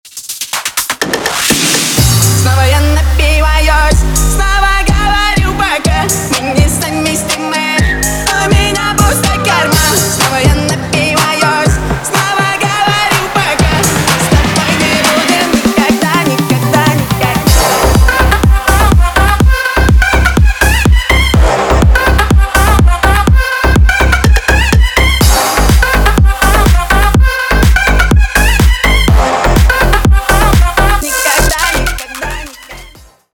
• Качество: 320 kbps, Stereo
Ремикс
клубные
громкие